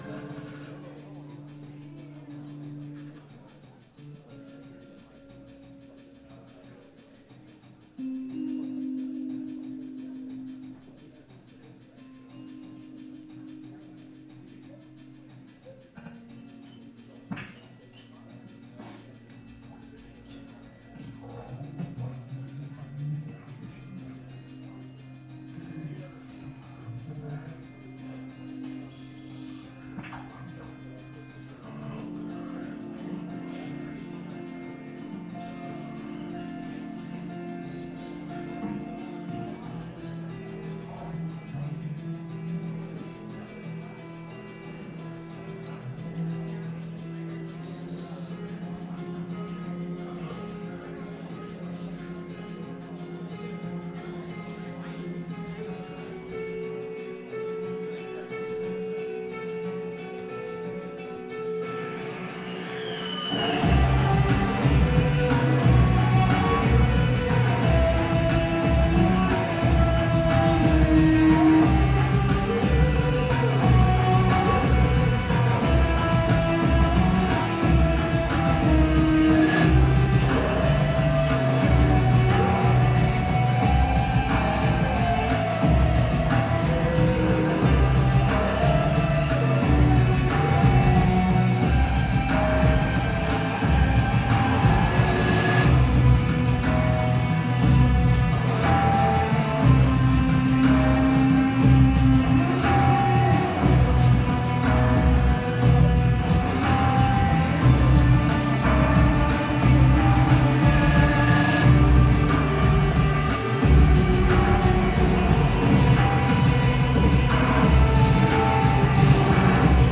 2007-04-25 Neumo’s – Seattle, WA